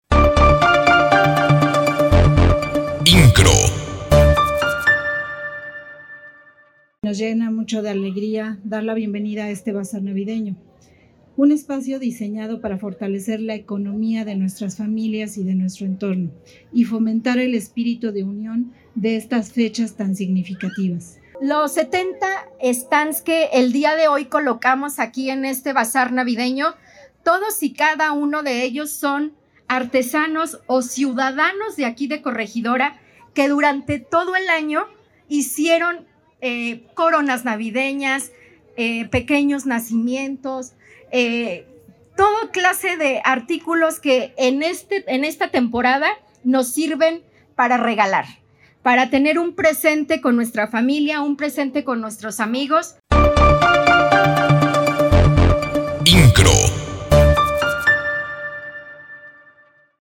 Lugar: Jardín Principal de El Pueblito, Corregidora.
Declaraciones: